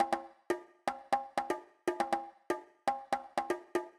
ITA Beat - Congas.wav